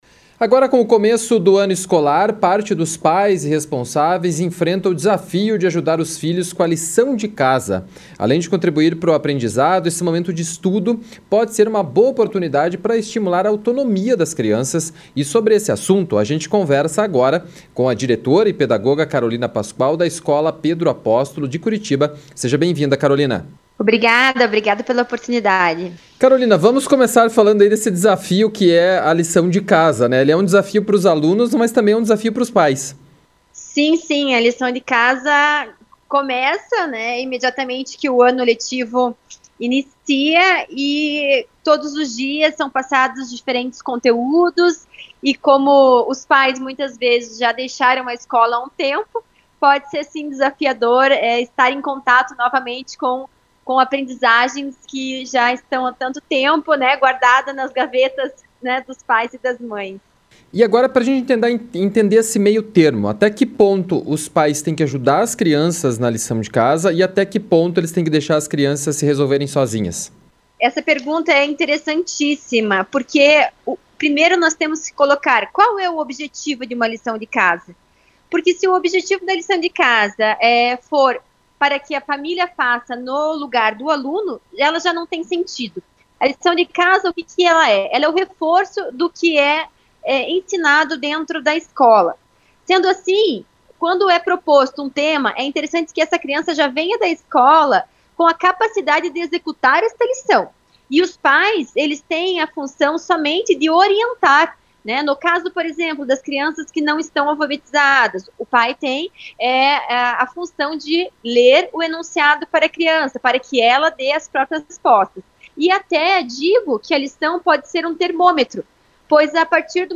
ENTREVISTA-LICAO-DE-CASA.mp3